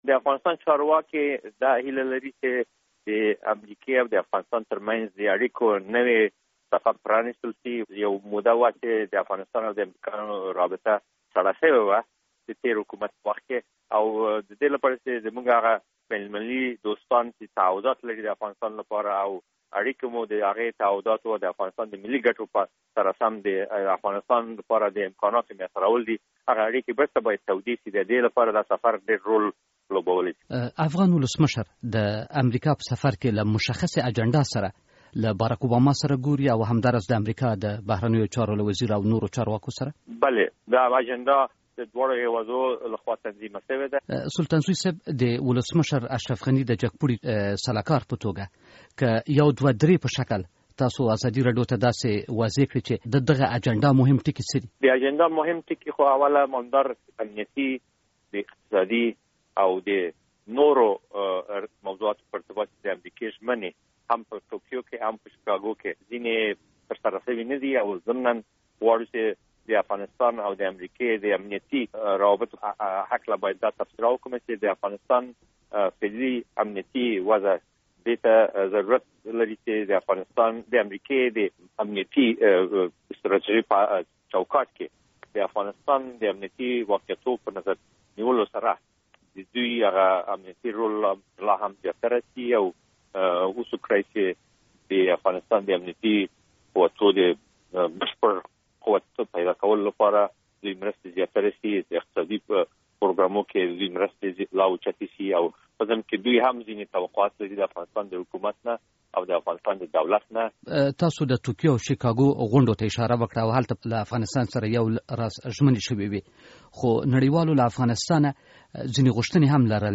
له داود سلطانزوی سره مرکه